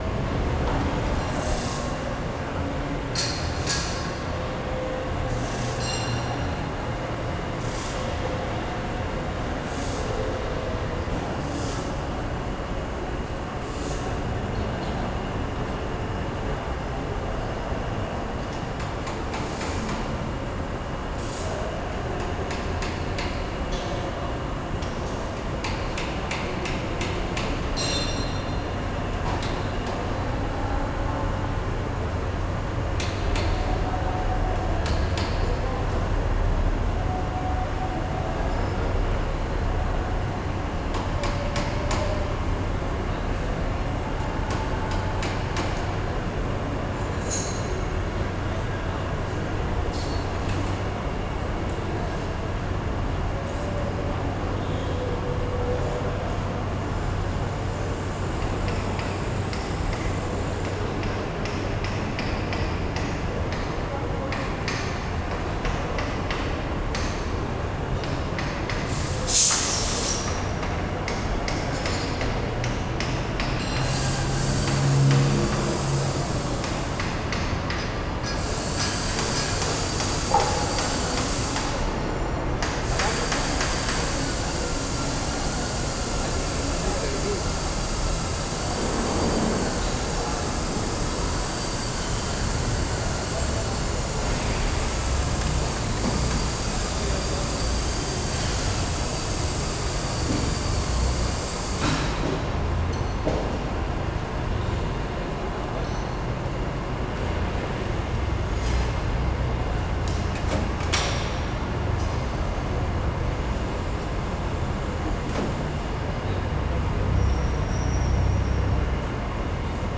warehouse_ambiance.wav